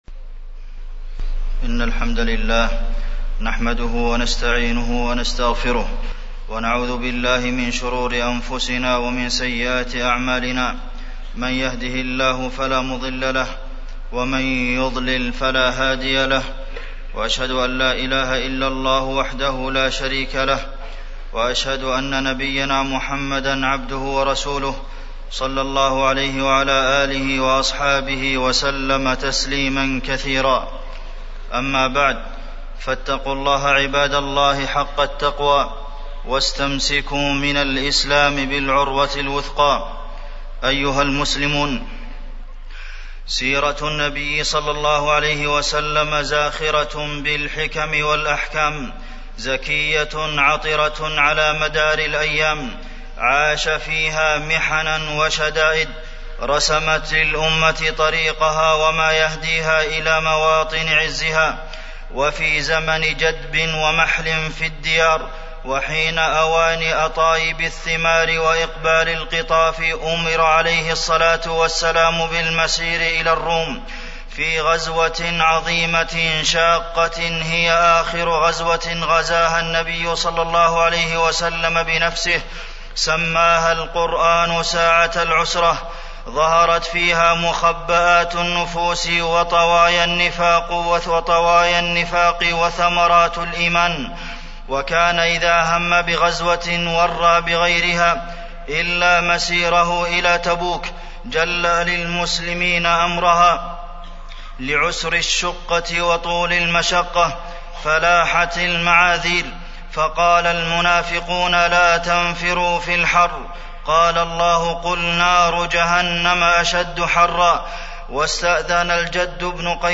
تاريخ النشر ١٦ ربيع الأول ١٤٢٧ هـ المكان: المسجد النبوي الشيخ: فضيلة الشيخ د. عبدالمحسن بن محمد القاسم فضيلة الشيخ د. عبدالمحسن بن محمد القاسم جيش العسرة